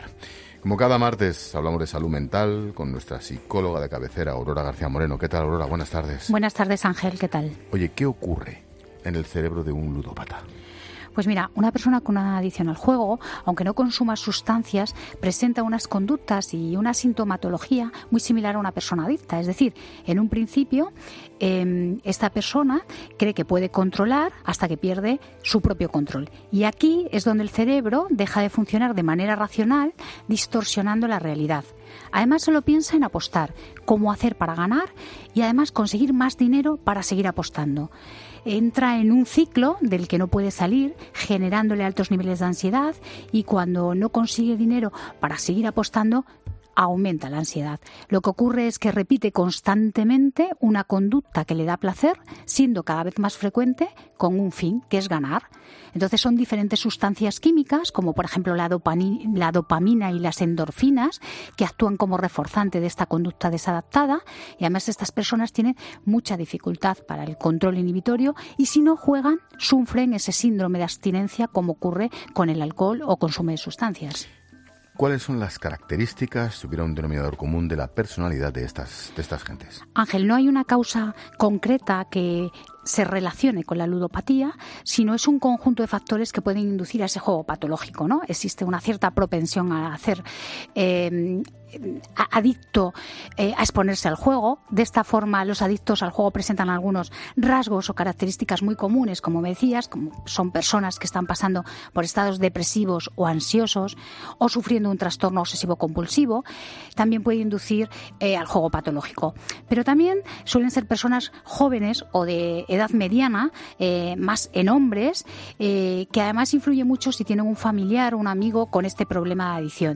Para poder lidiar con este tipo de situaciones la psicóloga ha enumerado una serie de recomendaciones: